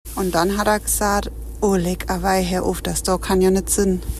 mit ihrem saarländischen Dialekt hinzu: